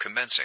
PILOT-Commencing.ogg